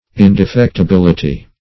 Search Result for " indefectibility" : The Collaborative International Dictionary of English v.0.48: Indefectibility \In`de*fect`i*bil"i*ty\, n. [Cf. F. ind['e]fectibilit['e].] The quality of being indefectible.